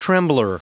Prononciation du mot trembler en anglais (fichier audio)
Prononciation du mot : trembler